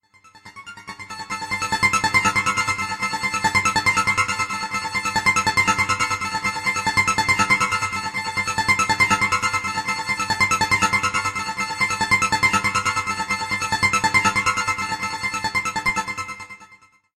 combining electronic sounds and minimalist savings.
Ringtone